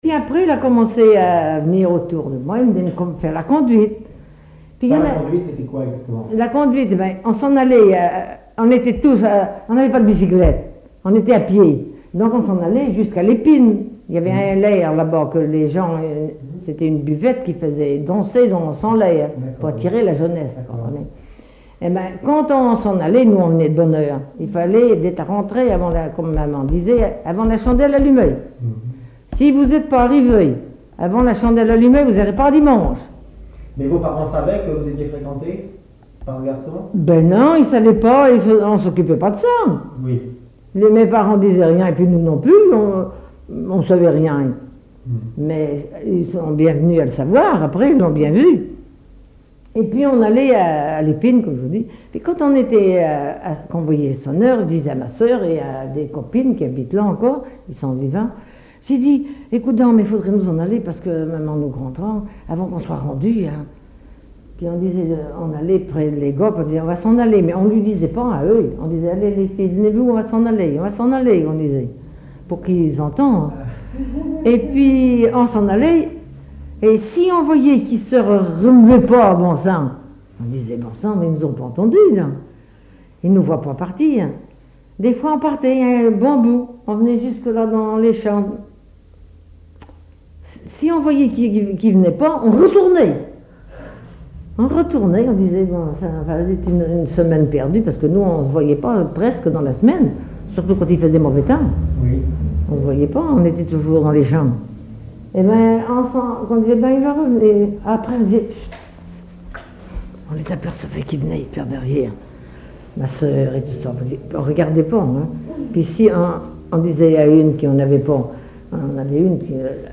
collecte en Vendée
Catégorie Témoignage